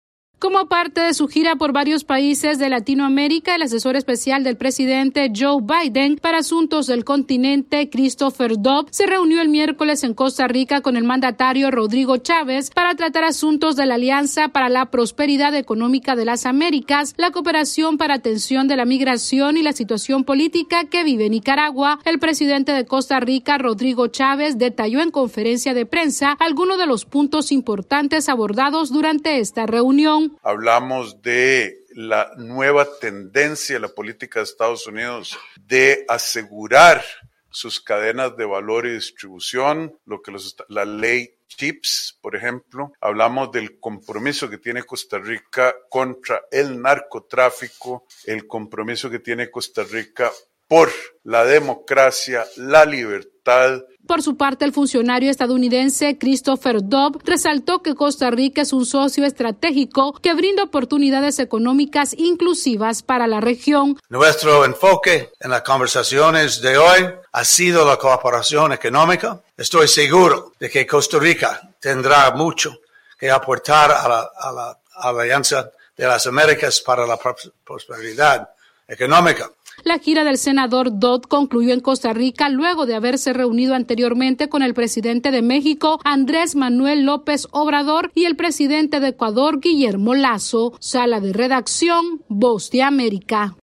AudioNoticias
El asesor especial del presidente de los Estados Unidos para América Latina, Christopher Dodd, concluyó su gira por Latinoamérica con una reunión con el presidente costarricense Rodrigo Chaves. Esta es una actualización de nuestra Sala de Redacción.